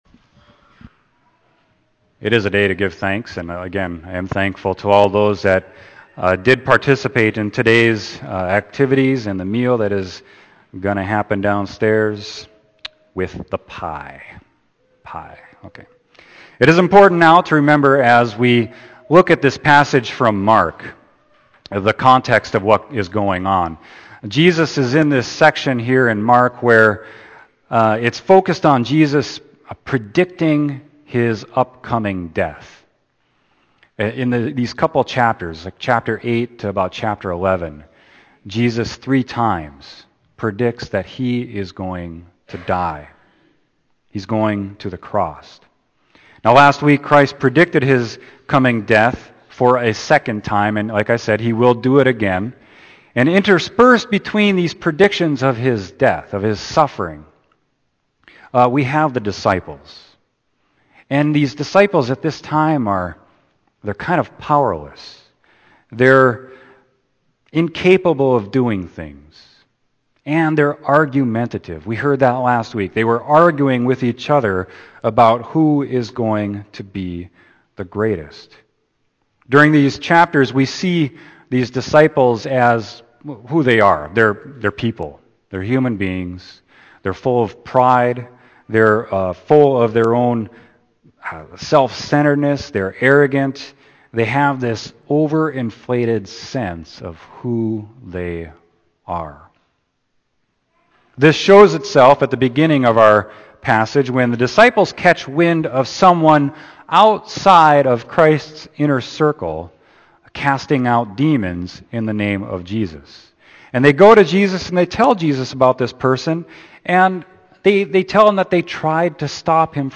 Sermon: Mark 9.38-50